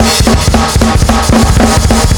Index of /m8-backup/M8/Samples/musicradar-metal-drum-samples/drums acoustic/220bpm_drums_acoustic